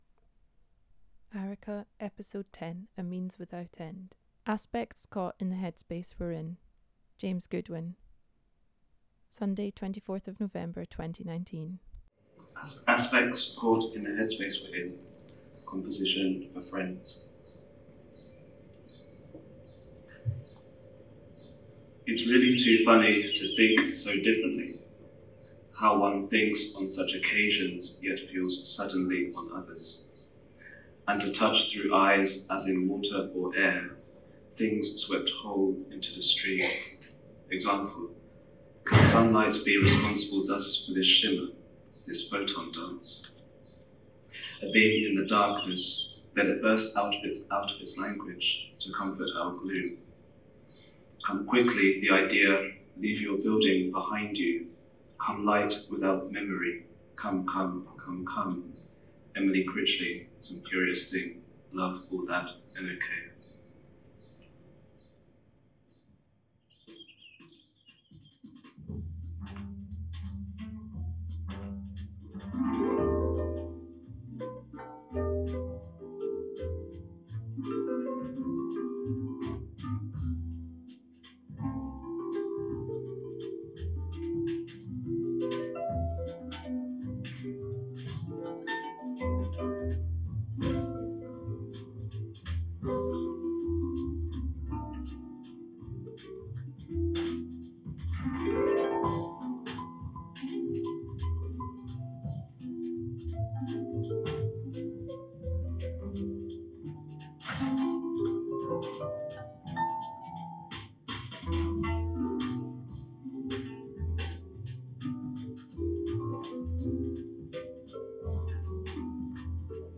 Venue Tramway, Glasgow
Reading